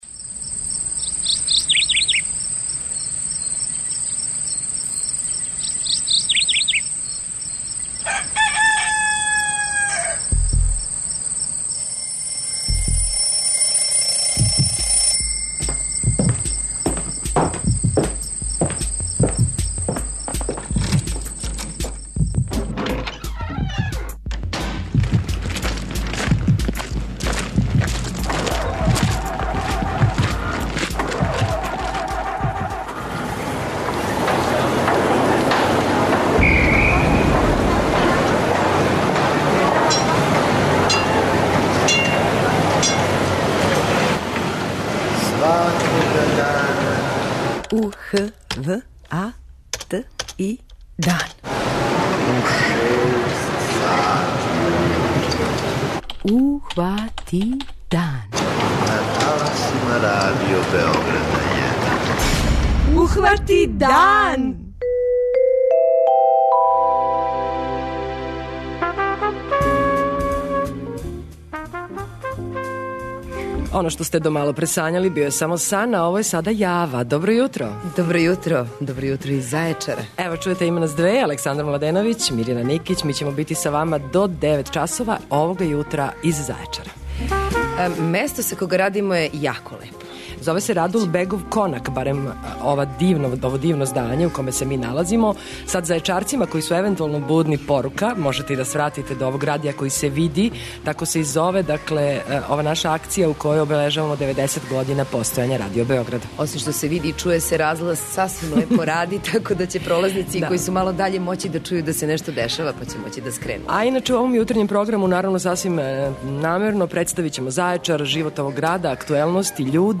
Овога јутра дан хватамо у Зајечару, који је нова станица на турнеји Радио Београда 1 којом обележавамо 90 година постојања нашег и Вашег радија!